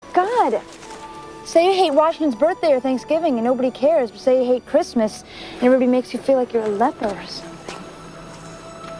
Tags: movie quote trivia pub quiz game time trivia